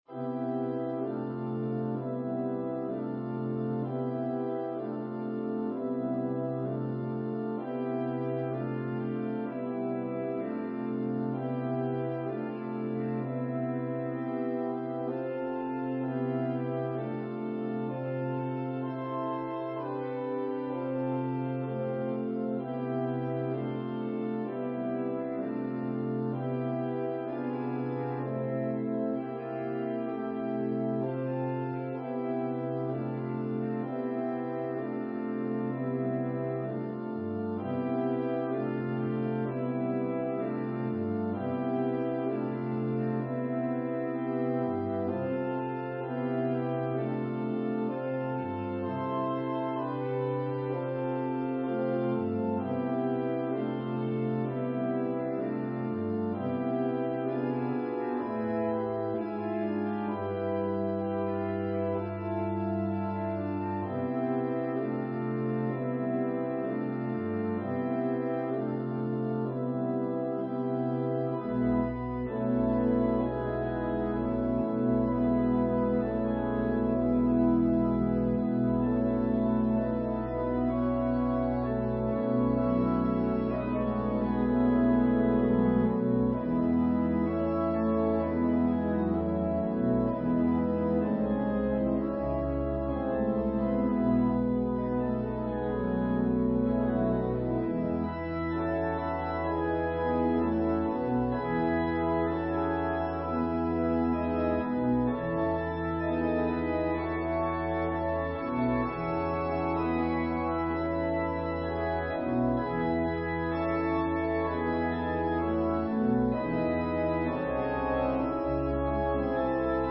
An organ solo rendition
Voicing/Instrumentation: Organ/Organ Accompaniment